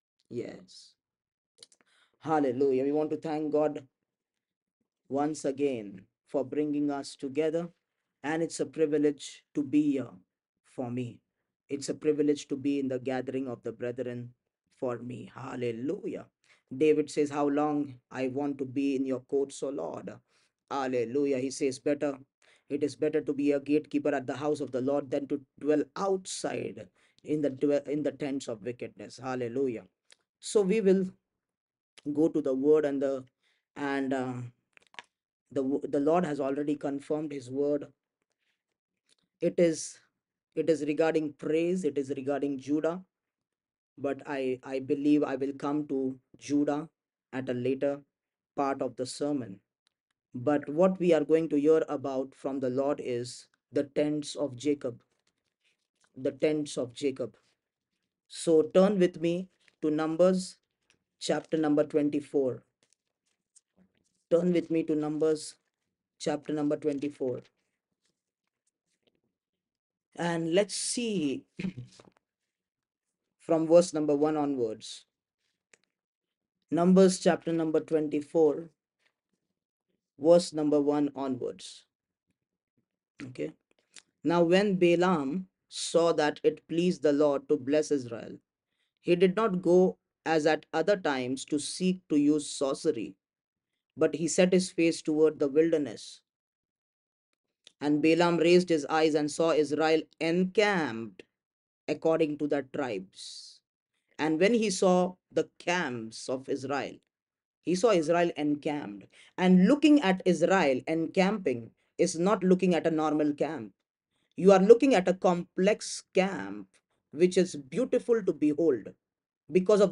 Tents of Jacob part 1 | International Friday Service